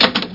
Dooropen Sound Effect
dooropen.mp3